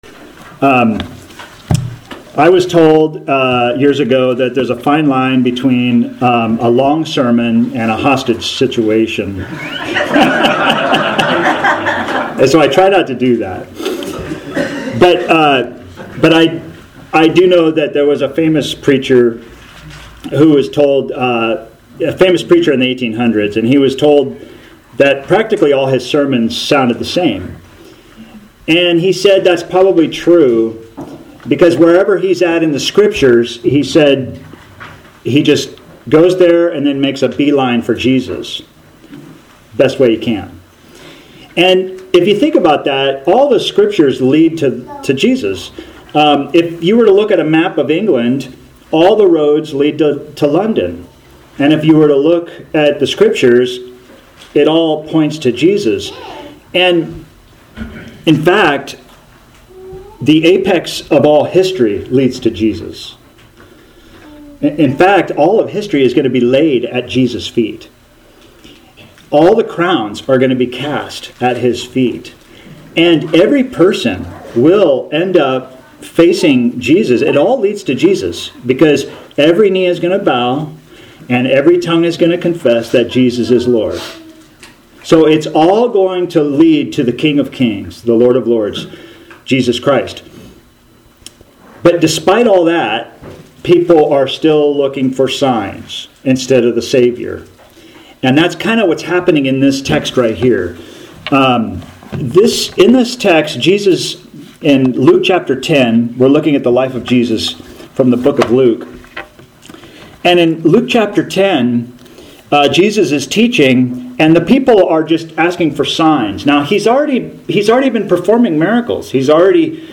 Author jstchurchofchrist Posted on January 9, 2024 January 9, 2024 Categories Sermons Tags Jesus , Luke - Gospel For All